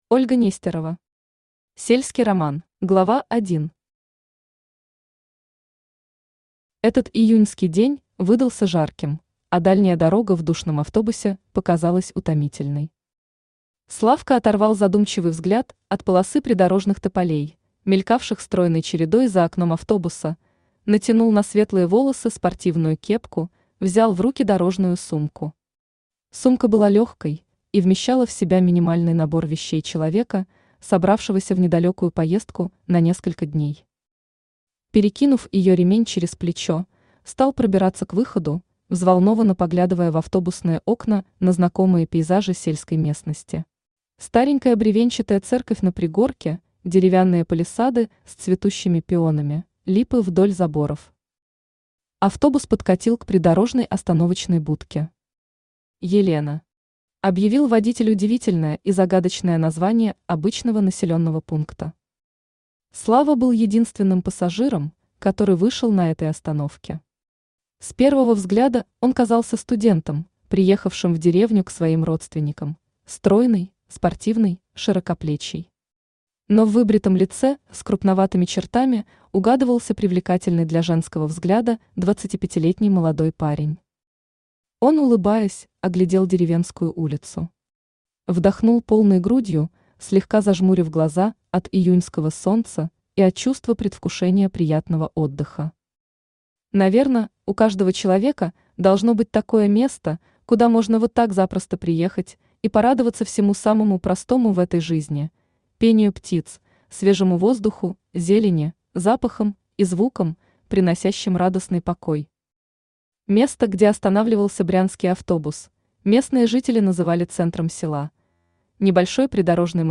Аудиокнига Сельский роман | Библиотека аудиокниг
Aудиокнига Сельский роман Автор Ольга Владимировна Нестерова Читает аудиокнигу Авточтец ЛитРес.